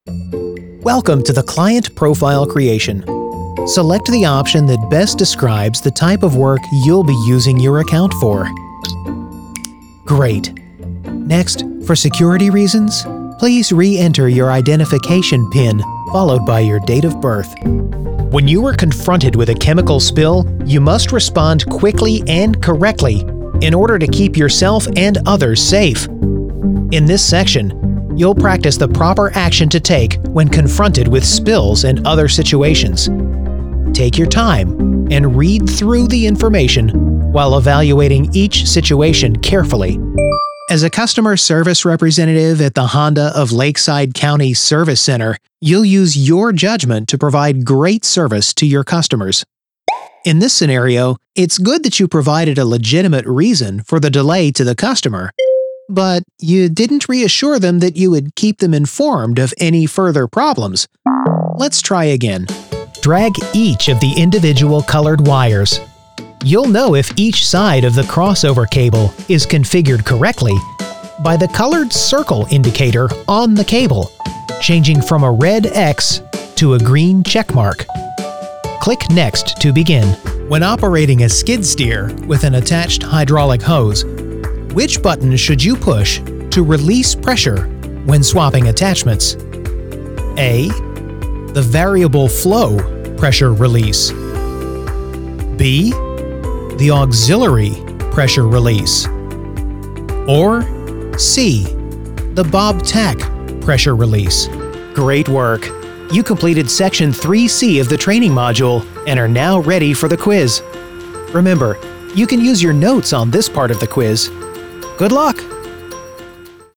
home studio, production, commercial, animation, video games, audiobooks
mid-atlantic
Sprechprobe: eLearning (Muttersprache):